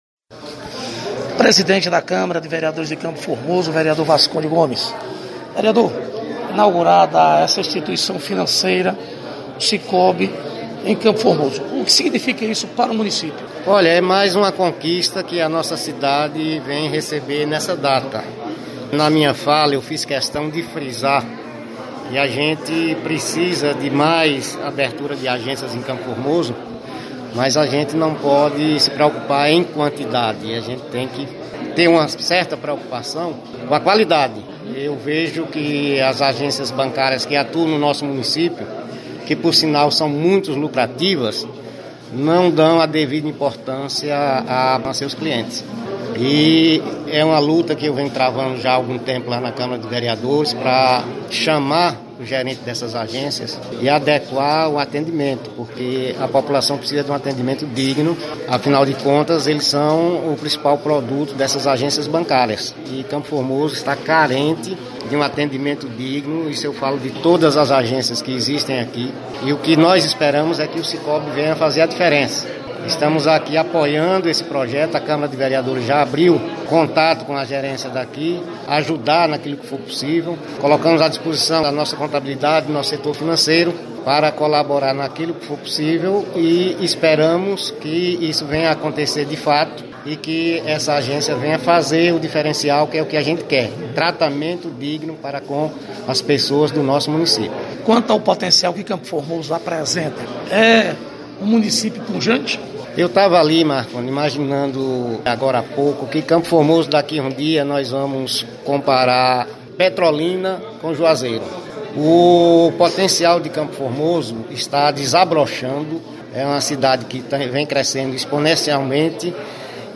Presidente da Câmara de vereadores de CFormoso Vasconde Gomes- inauguração do Sicoob